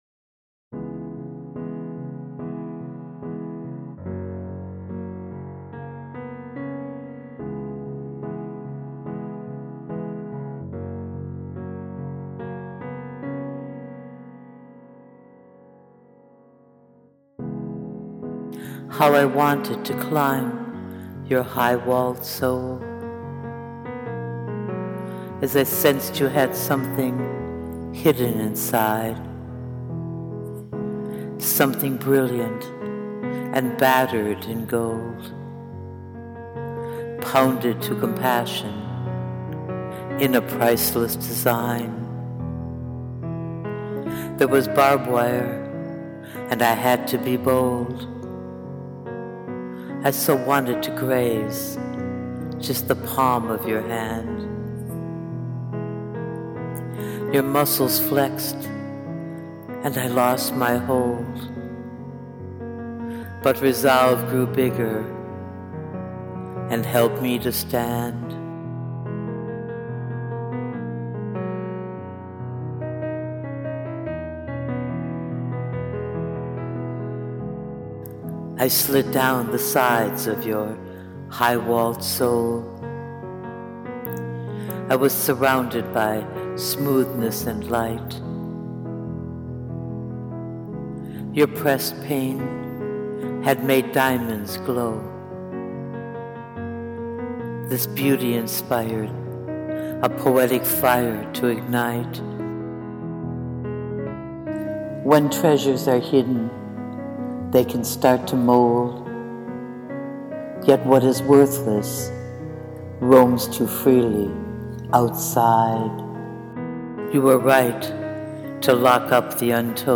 The voice brings the poem alive.